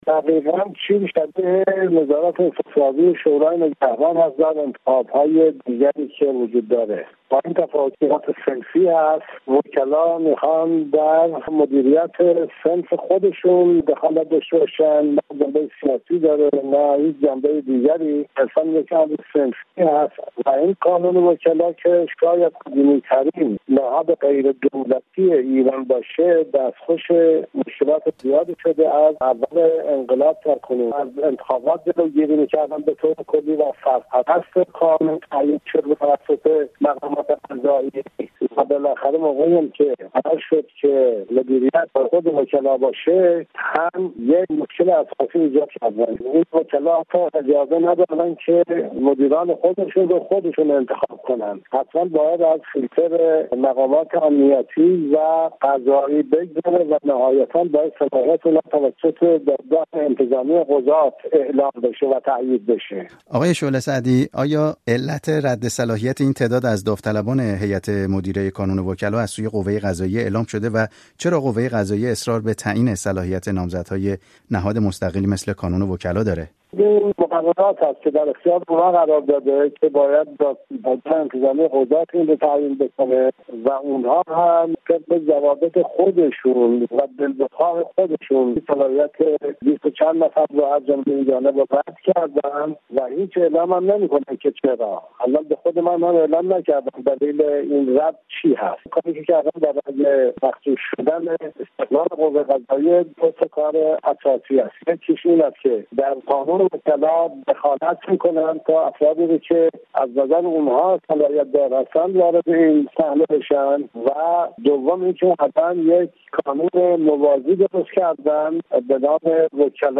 قاسم شعله سعدی ، یکی از این وکلای رد صلاحیت شده، در گفت‌وگو با رادیو فردا، ضمن تأیید رد صلاحیت خود در این دوره، این اقدام دادگاه عالی انتظامی قضات را خلاف اصل استقلال کانون وکلا خوانده است:
گفت‌وگوی